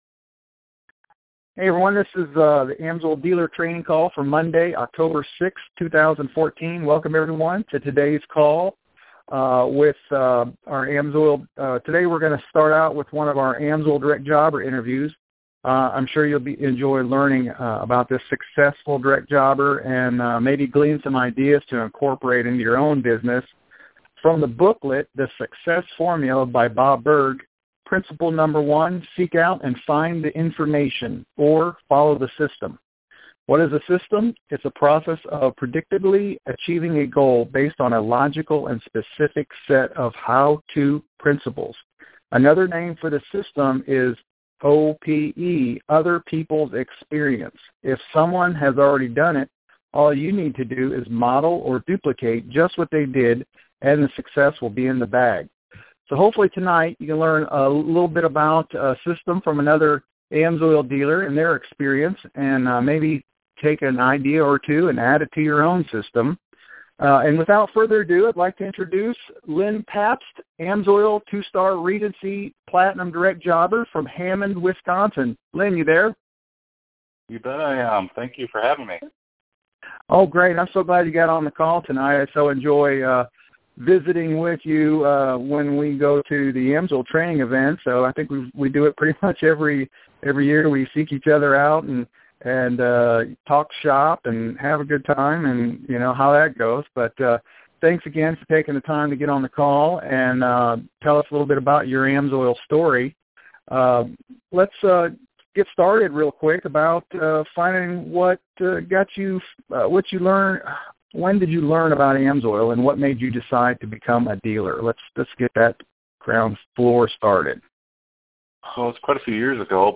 This weeks AMSOIL Dealer training call we interview AMSOIL Direct Jobber